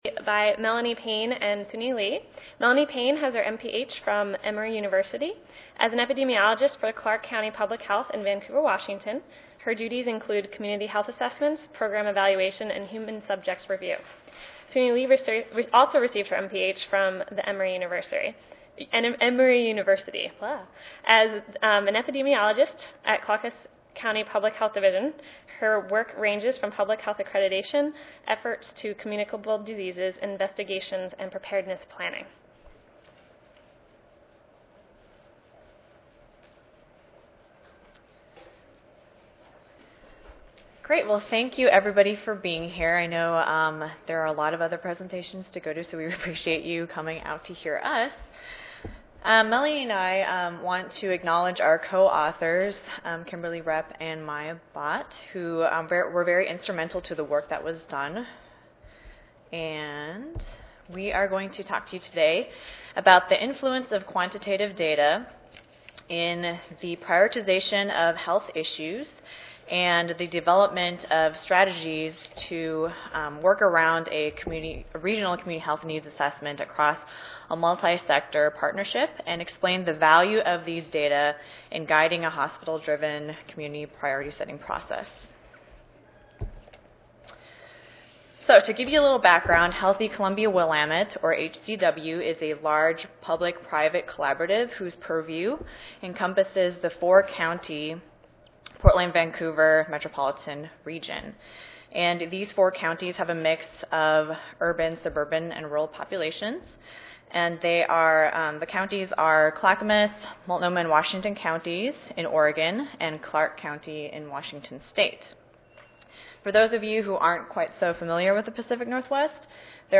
This presentation will describe the degree to which the quantitative health status assessment influenced the final selection of priorities and, consequently, health improvement strategies in these CHNAs. Presenters will also discuss the relative contribution of quantitative data compared with qualitative data (e.g., stakeholder interviews, focus groups) from the perspectives of both hospital and local public health department representatives.